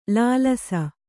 ♪ lālasa